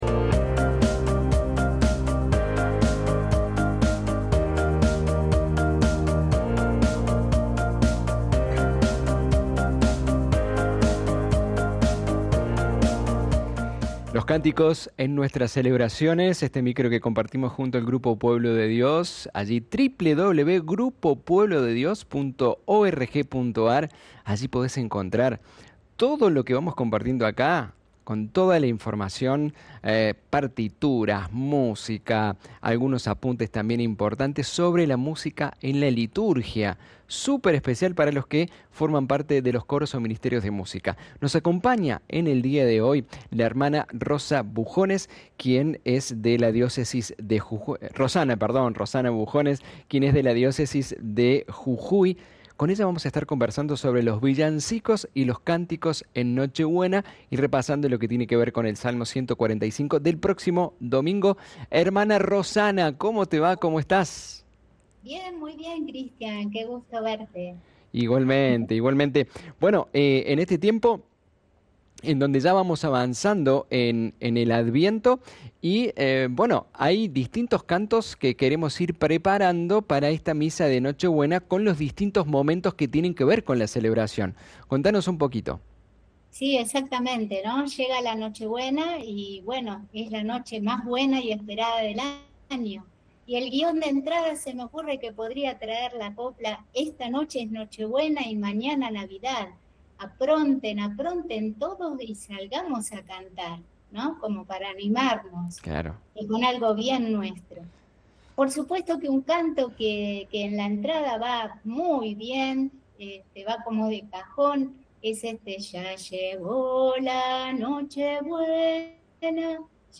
Esta propuesta se realiza junto a los integrantes del Grupo Pueblo de Dios, con el objetivo de profundizar en el sentido de la música y el canto dentro de nuestras celebraciones de fe.